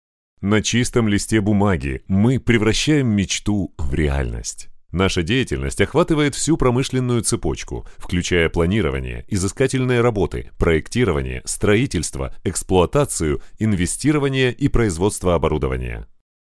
俄语样音试听下载
俄语配音员（男1）